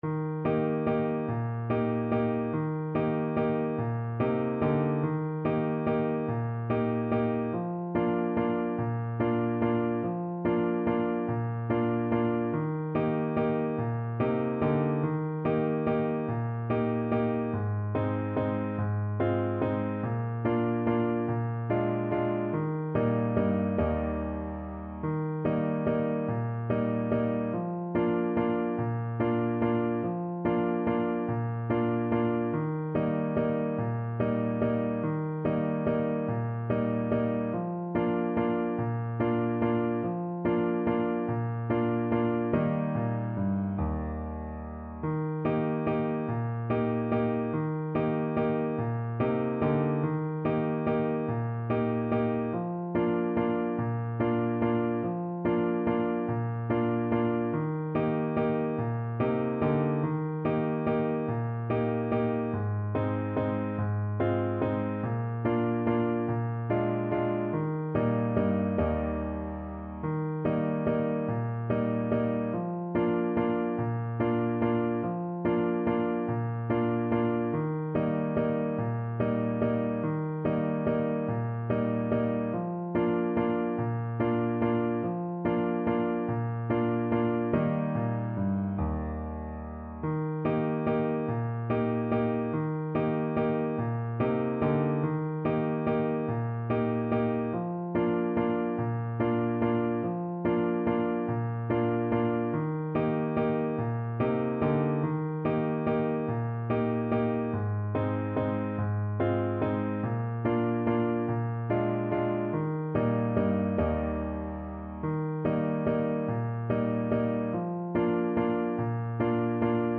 Steady one in a bar .=c.48
3/8 (View more 3/8 Music)
Traditional (View more Traditional Saxophone Music)